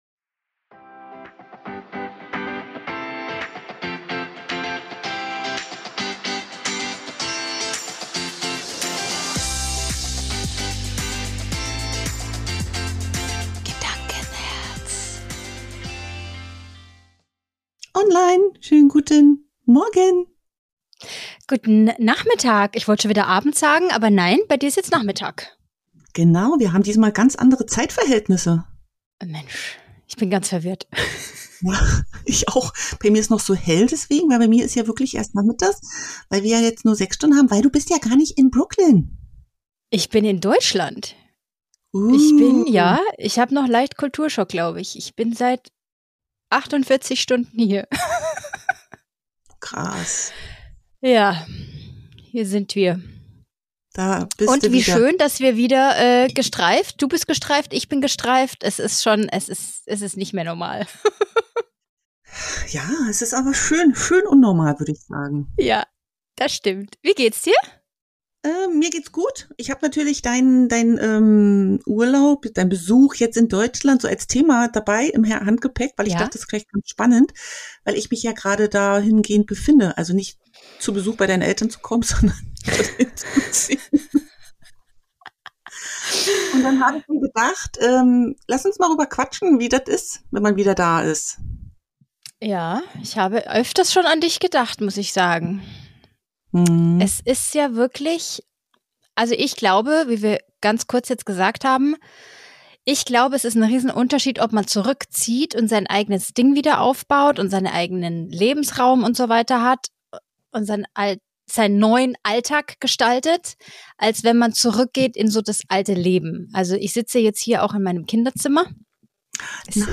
Wir leben beide im Ausland und sprechen darüber wie das wirklich ist. Welche Herausforderungen gibt es, wie ist es nach Deutschland zurück zu kommen und ist man so verändert, dass man sich wieder anpassen kann?